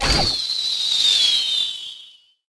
From The Cutting Room Floor Jump to navigation Jump to search File File history File usage Metadata Clash_Royale_archer_queen_attack_02.ogg  (Ogg Vorbis sound file, length 2.5 s, 67 kbps) This file is an audio rip from a(n) Android game.
Clash_Royale_archer_queen_attack_02.ogg